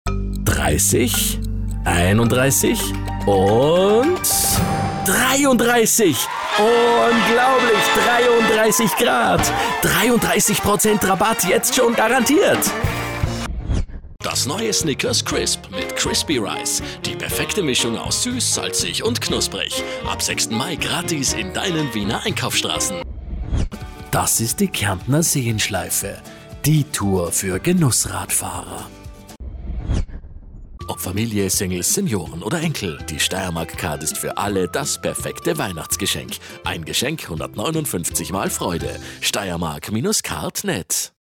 Stimme für Hörfunk- & TV-Spots seit 1992
Radiowerbung (LG Wärmepumpen, ÖkoFEN Pelletsheizungen, Restplatzbörse, Subaru):